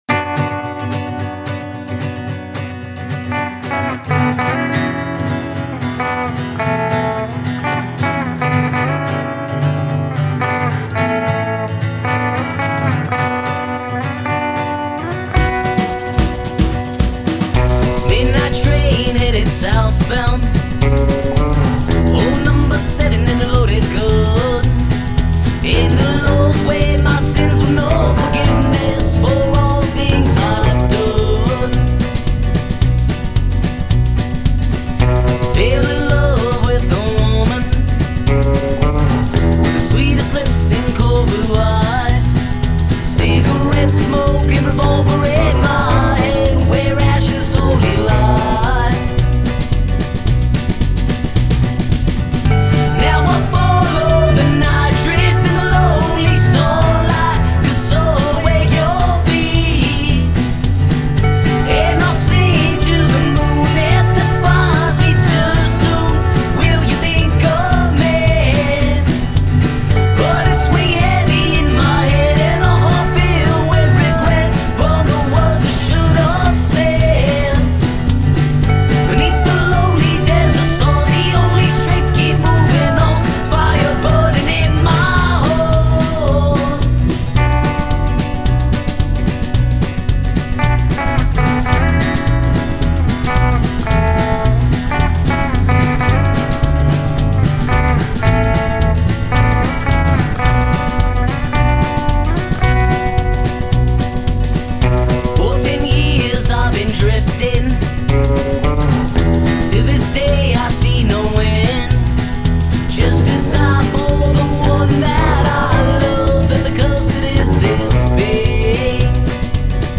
Country Album
Honky-Tonk, Bluegrass, Gypsy Jazz, Westerns